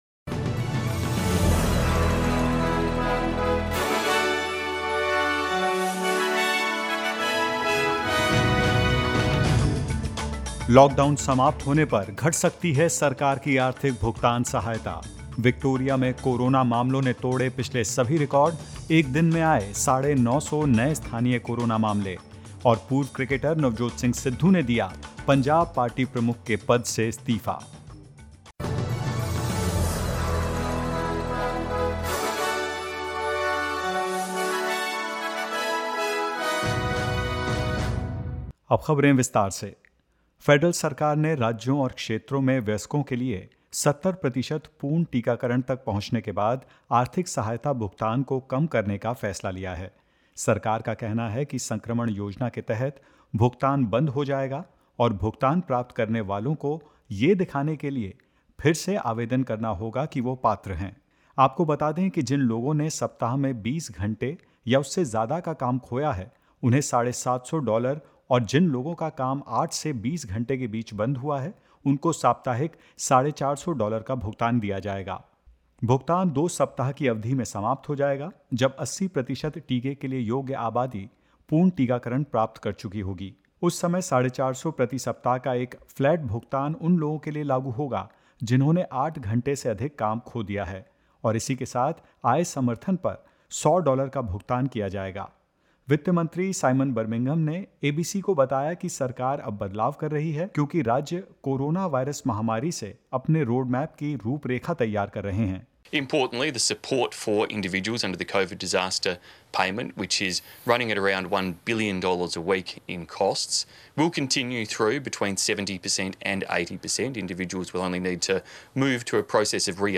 SBS HINDI News 29 September 2021: Worker support payments to be cut after the lockdown
In this latest SBS Hindi News bulletin of Australia and India: Victoria records 950 new COVID-19 cases - its highest daily figure since the start of the pandemic; From October 11th two fully vaccinated people can visit aged care residents in NSW and more.